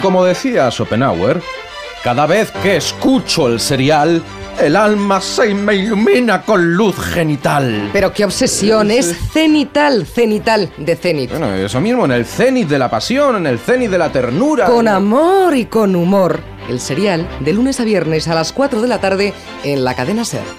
Promoció del programa